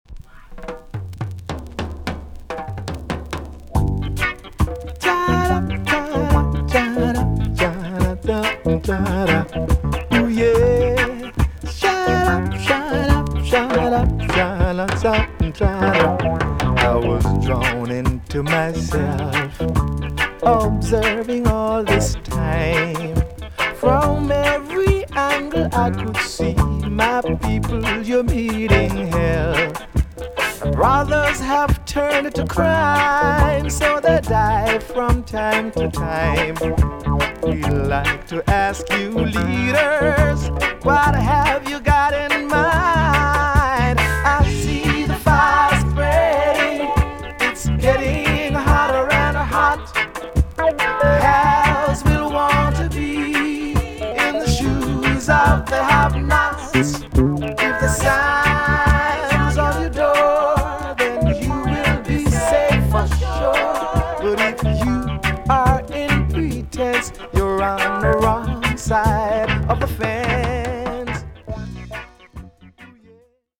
TOP >LP >VINTAGE , OLDIES , REGGAE
B.SIDE EX- 音はキレイです。